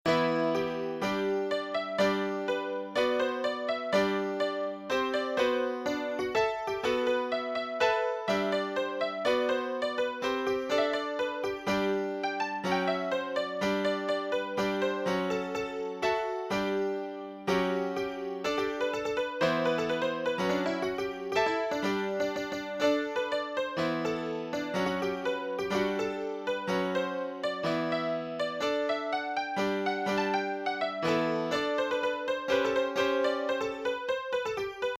FSM and FSM - Honky-tonk Piano and Acoustic Grand Piano [ MIDI ] [